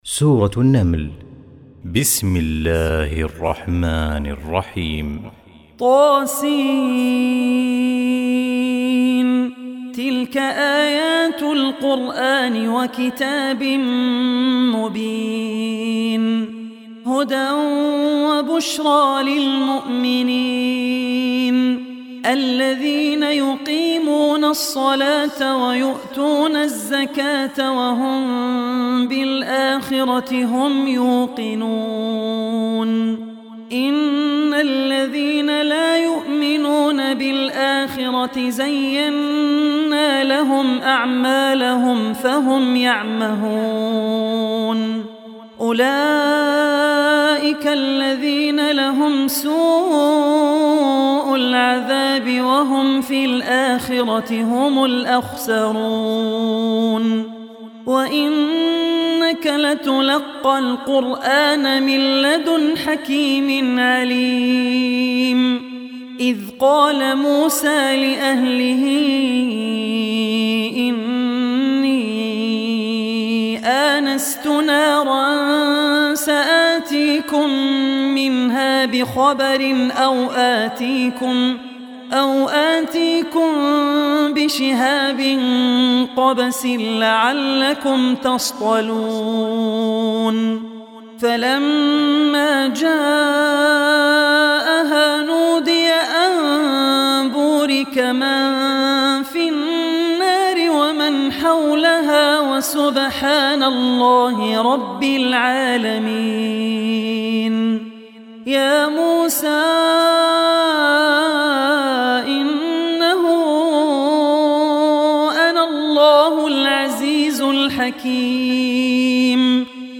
Surah Naml Recitation by Abdul Rehman Al Ossi
Surah Naml, listen online mp3 tilawat / recitation in the voice of Sheikh Abdul Rehman Al Ossi.